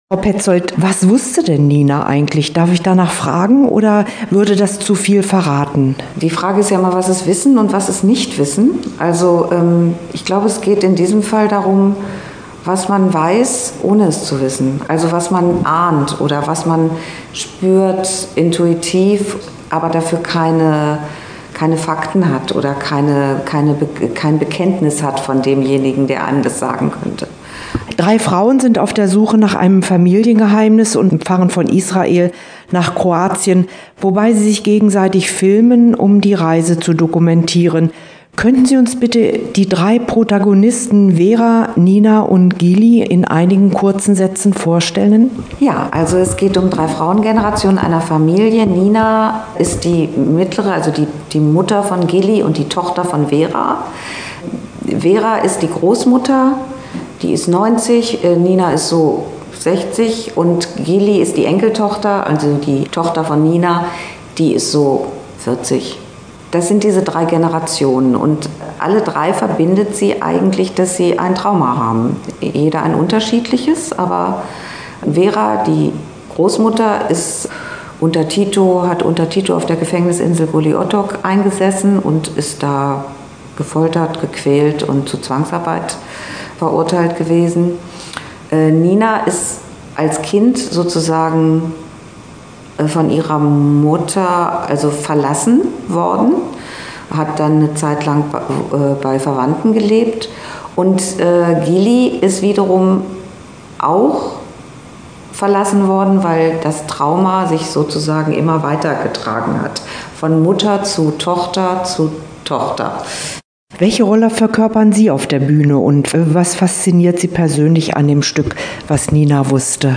Interview-Was-Nina-wusste.mp3